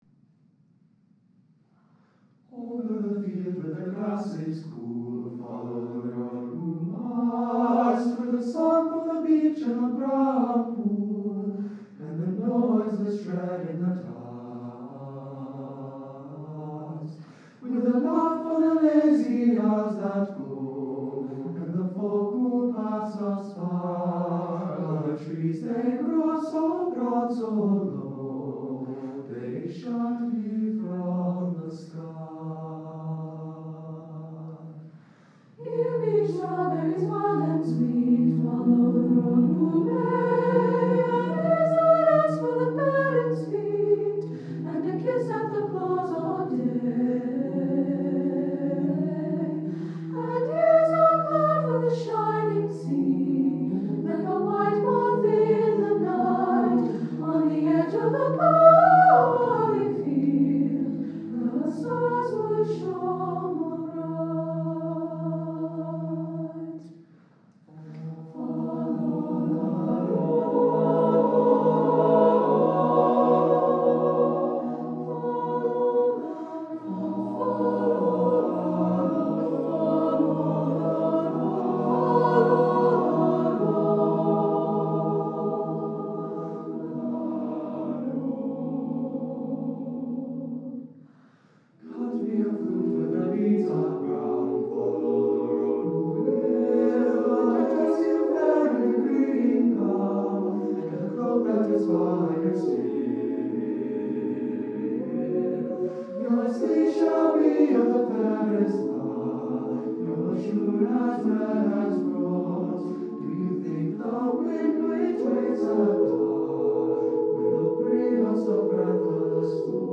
SSATB a cappella